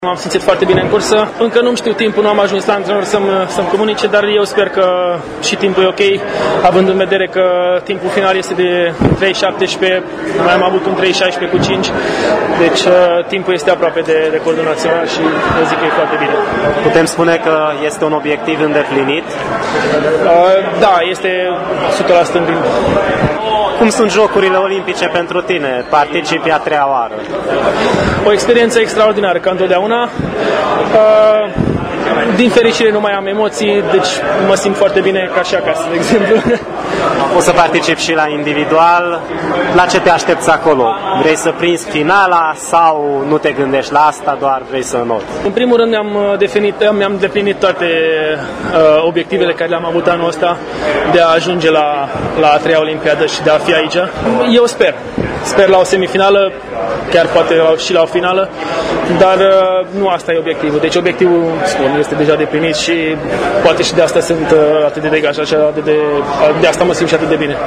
Un interviu audio